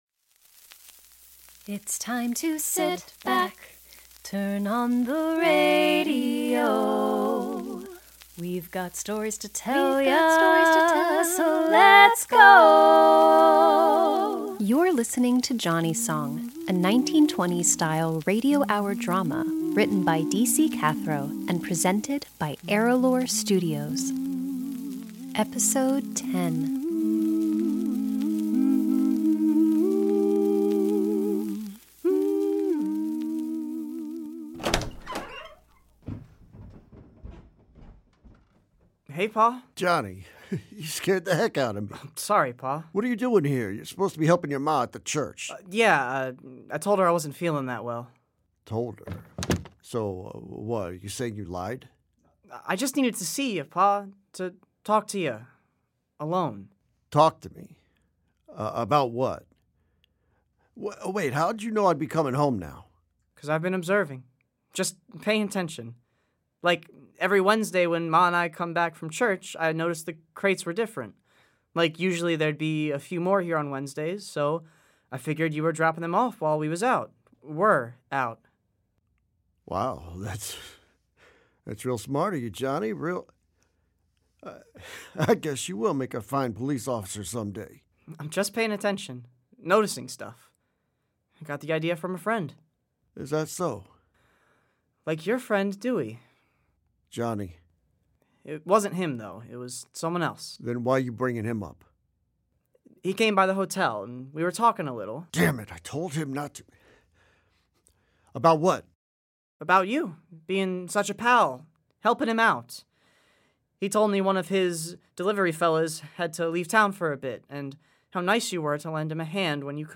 Johnnie's Song: A 1920s Style Radio Hour Drama Podcast - Episode 10: A Day to Remember | Free Listening on Podbean App